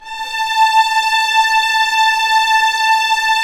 Index of /90_sSampleCDs/Roland LCDP13 String Sections/STR_Violins IV/STR_Vls7 _ Orch